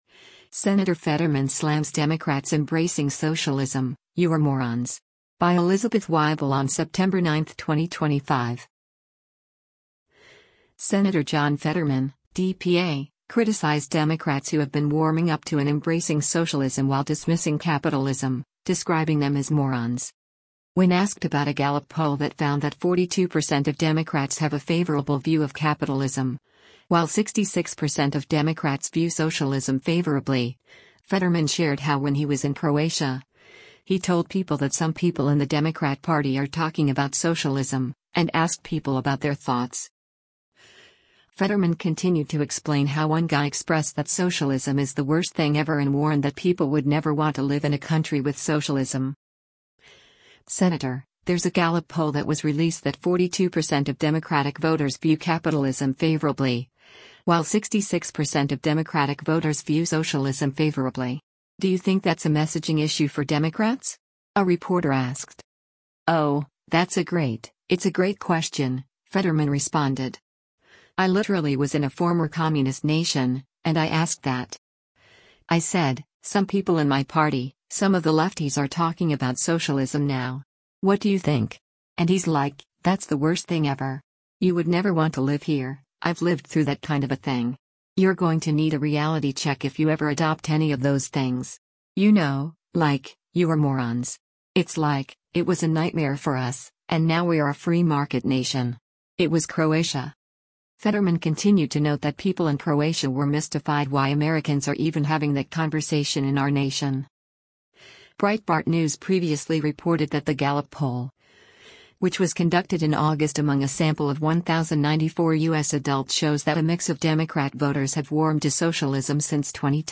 “Senator, there’s a Gallup poll that was released that 42 percent of Democratic voters view capitalism favorably, while 66 percent of Democratic voters view socialism favorably. Do you think that’s a messaging issue for Democrats?” a reporter asked.